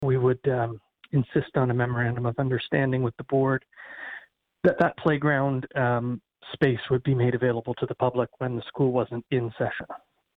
Smithfield Public School volunteers have asked council for $85,000 and council has agreed but with a stipulation, as mayor of Brighton Brian Ostrander explains.